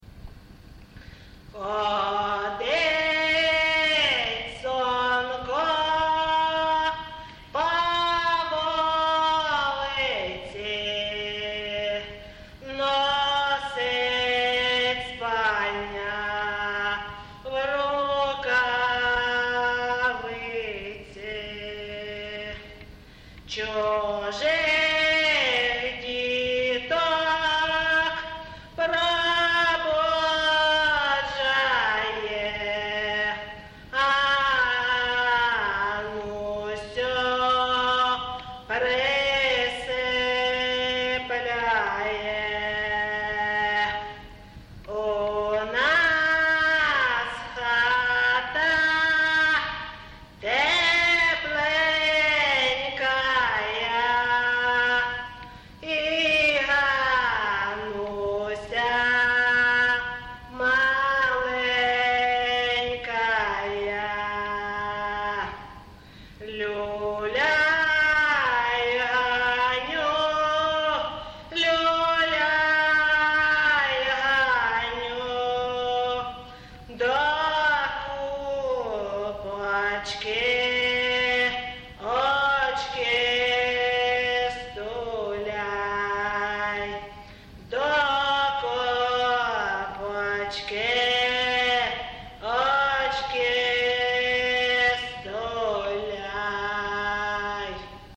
ЖанрКолискові
Місце записус. Ковалівка, Миргородський район, Полтавська обл., Україна, Полтавщина
(Виконавиця співає пісні, що не є зразком місцевої традиції)